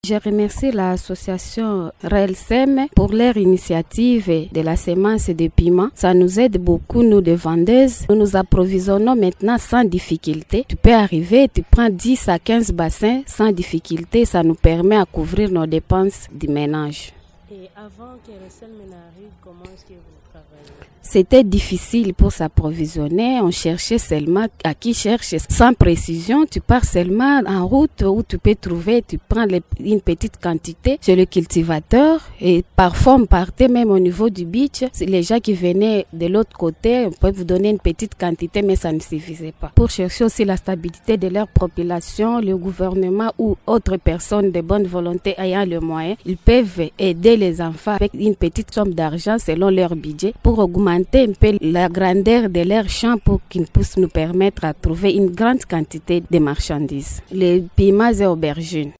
l’une des vendeuses du piment au marché de RVA